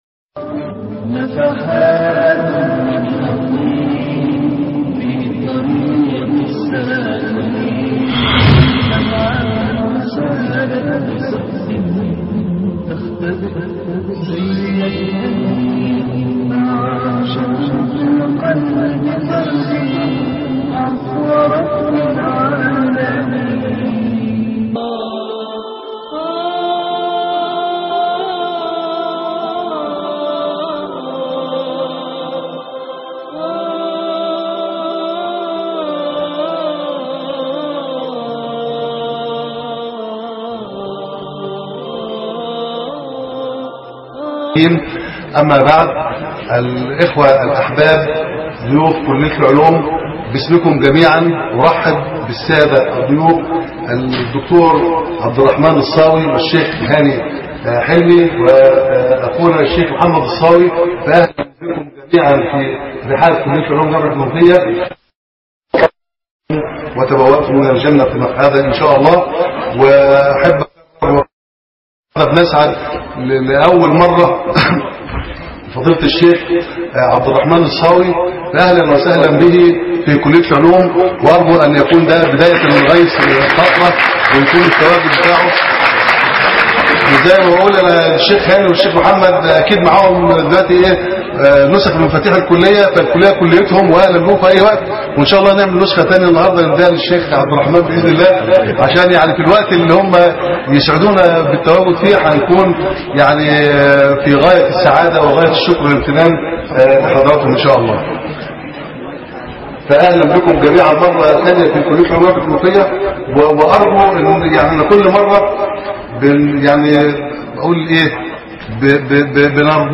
من هنا نبدأ- ندوات ودروس من المساجد